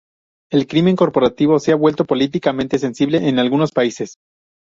Uitgesproken als (IPA)
/senˈsible/